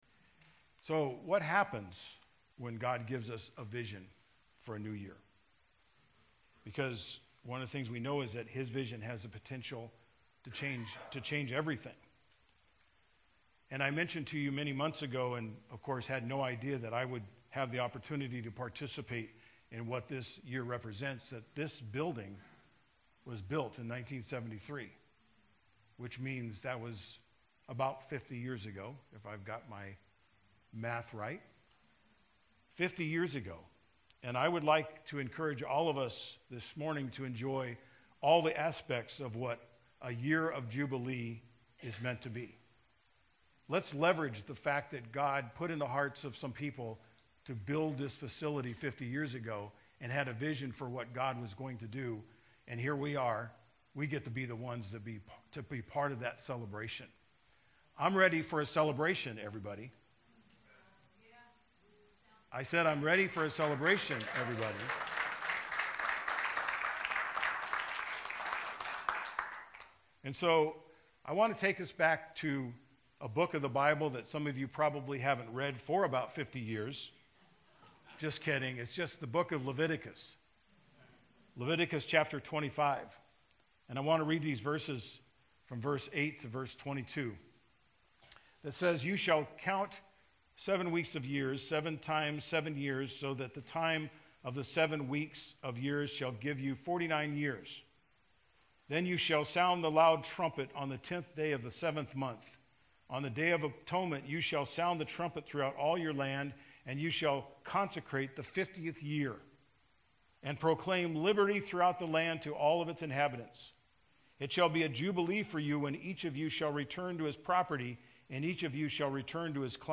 January 1, 2023- New Year’s Day Service